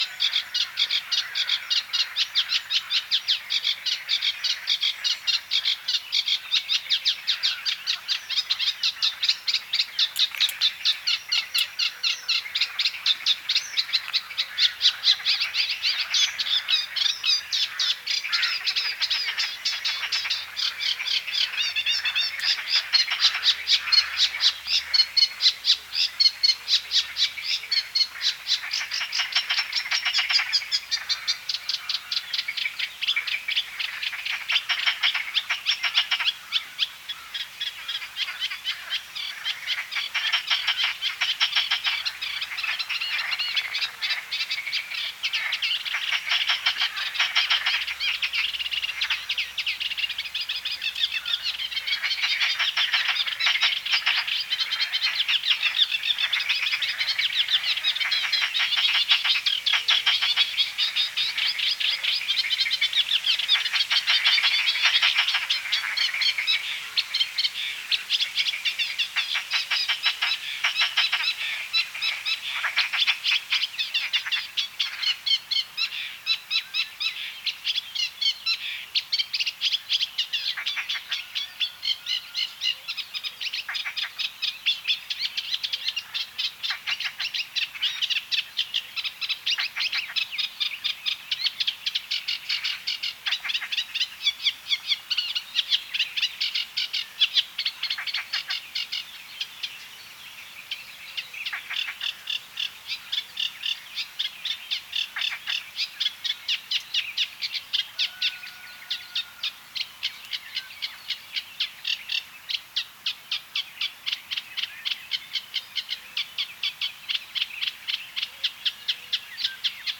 Data resource Xeno-canto - Bird sounds from around the world